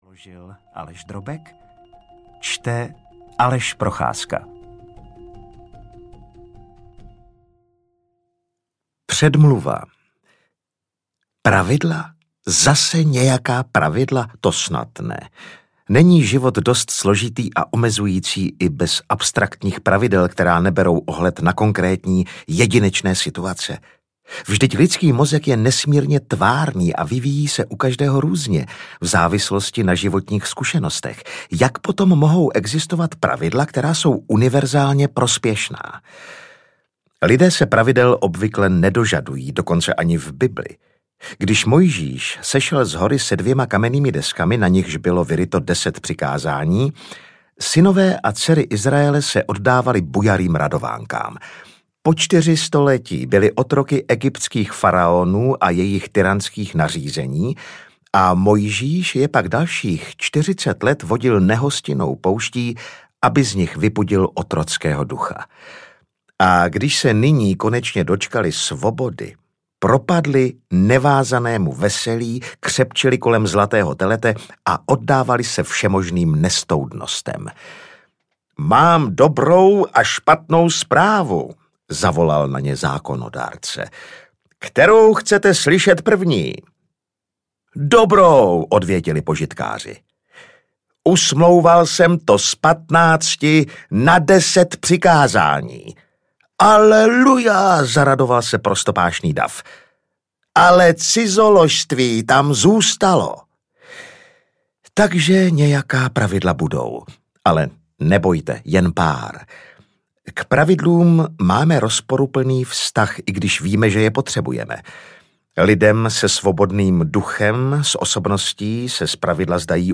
12 pravidel pro život audiokniha
Ukázka z knihy